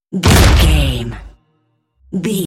Dramatic hit door slam blood
Sound Effects
heavy
intense
dark
aggressive
hits